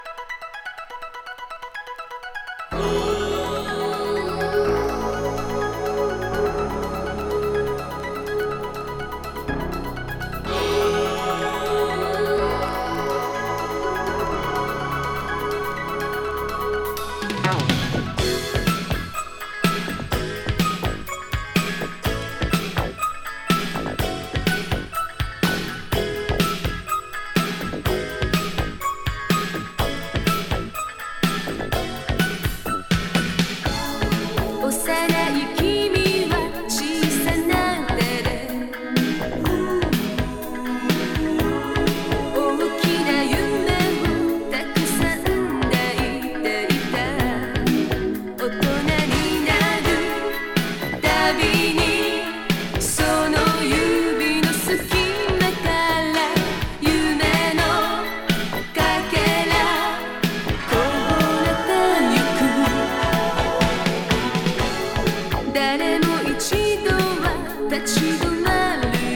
80's ロック / ポップス